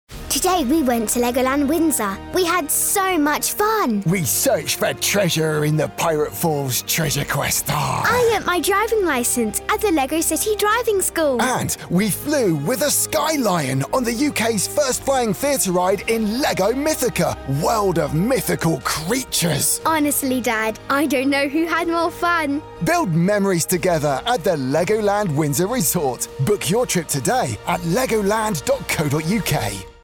An assured voice of maturity, experience,authority and gravitas. Perfect for inspiring trust, confident with a soothing and evocative tone.
RP, Geordie, Yorkshire, Scouse Clients Include: V&A Museum, Big Finish/BBC (Doctor Who), IBM, Smooth Radio, English Heritage, MSD Medical $455.37 per hour* + $8.07 booking fee* ADD